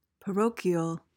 PRONUNCIATION:
(puh-ROH-kee-uhl)